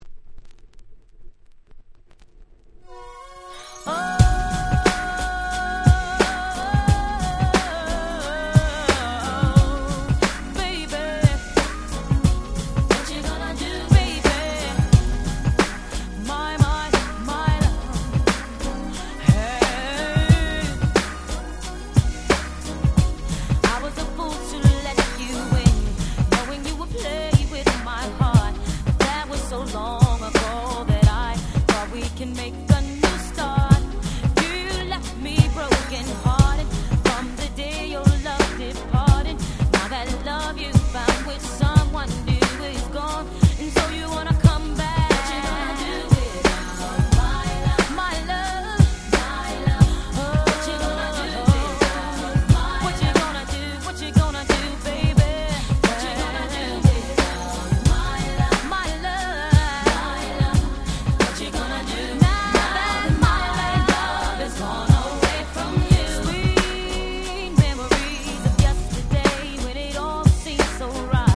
94' Smash Hit R&B !!